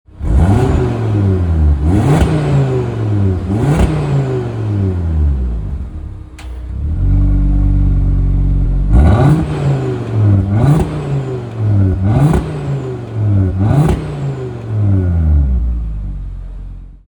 XKK-G81-revs.mp3